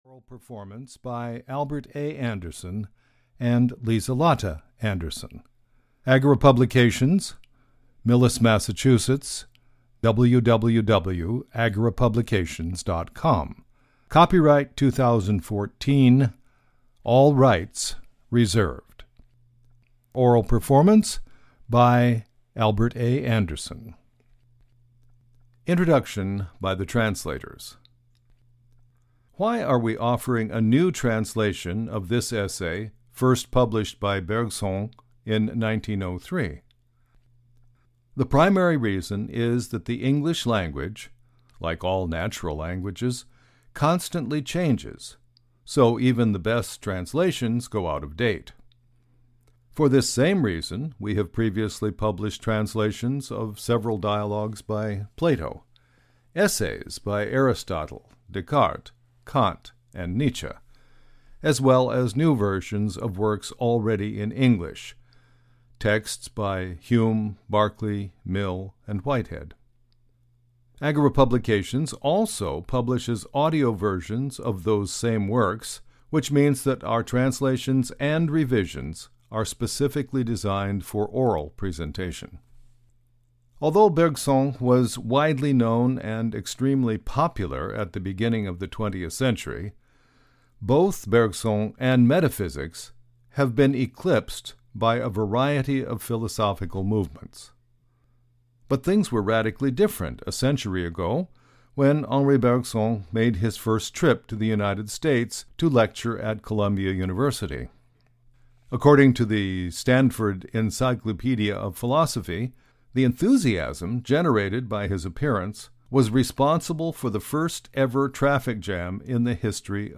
An Introduction to Metaphysics (EN) audiokniha
Ukázka z knihy